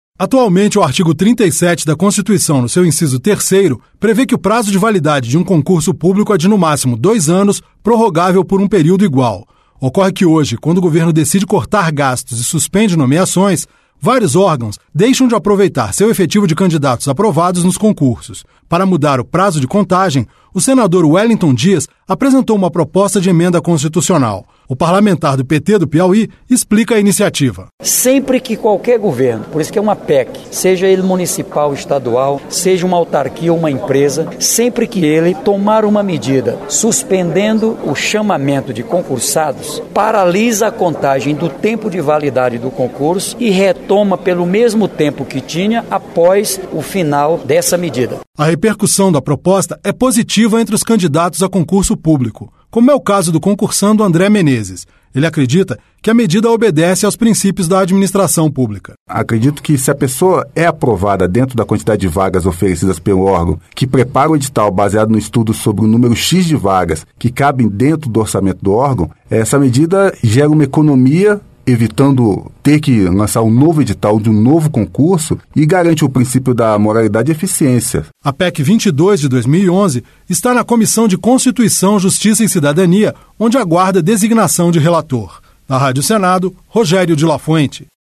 Para mudar o prazo de contagem, o senador Wellington Dias, apresentou uma proposta de emenda constitucional. O parlamentar do PT do Piauí explica a iniciativa.